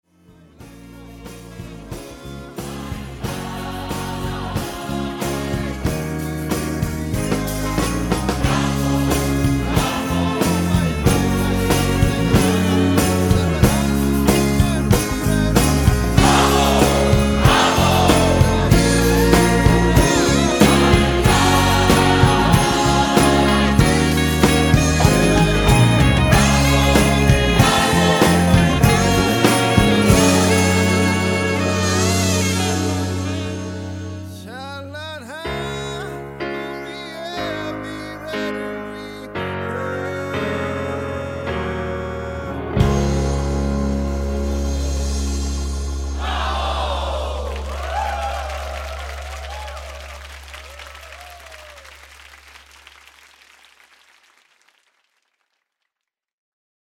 음정 원키 4:23
장르 가요 구분 Voice MR